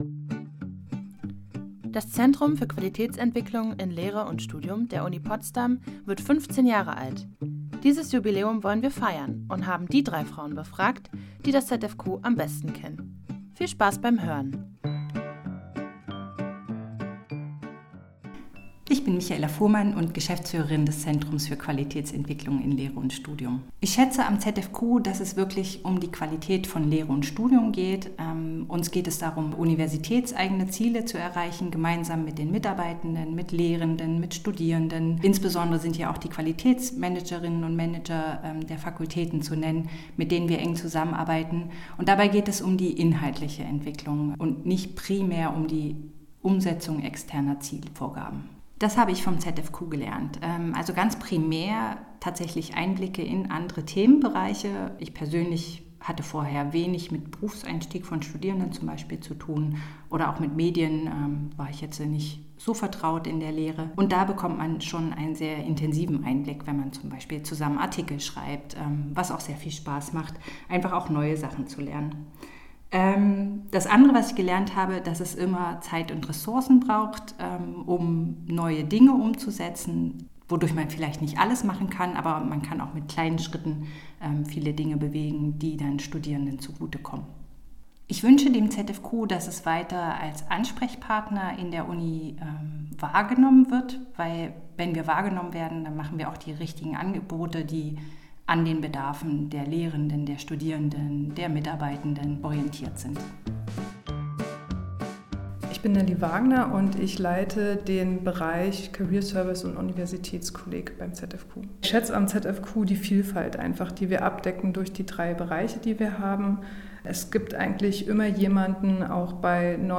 Interview
15_Jahre_ZfQ_Drei_Fragen_Drei_Frauen.mp3